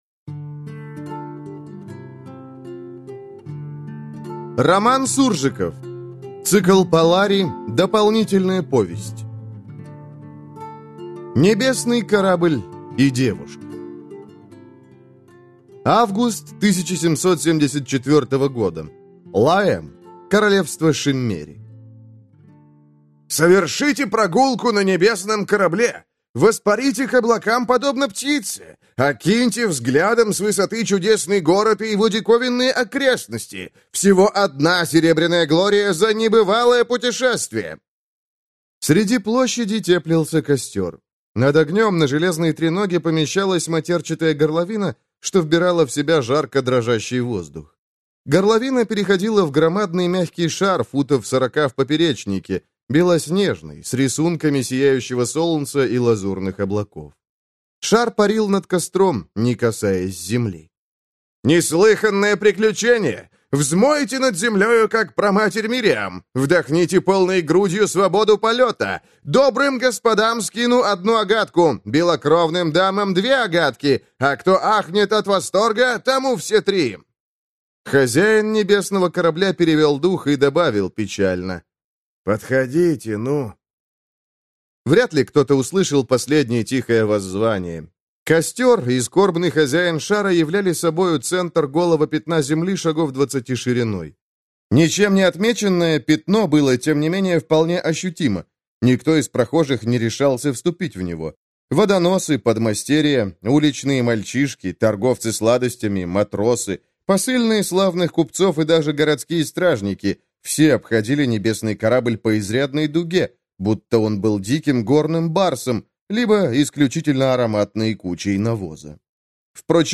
Аудиокнига Небесный корабль и девушка | Библиотека аудиокниг